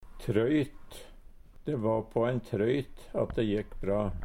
trøyt - Numedalsmål (en-US)